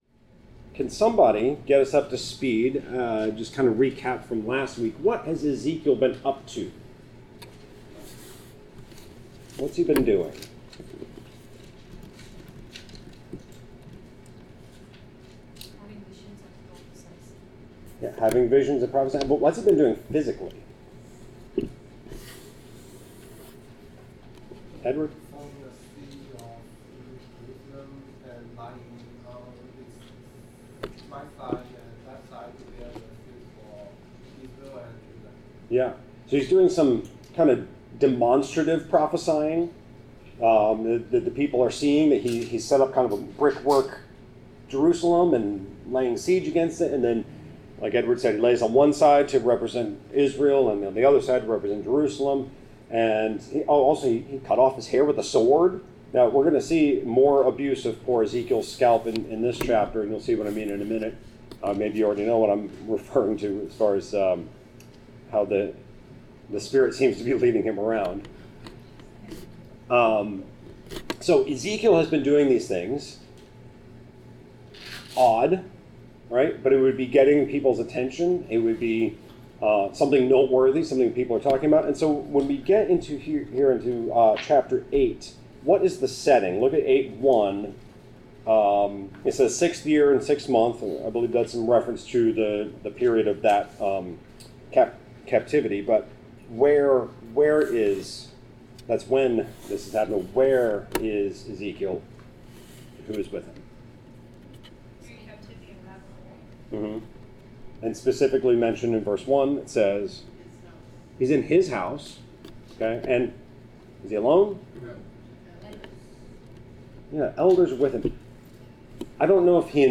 Bible class: Ezekiel 8-11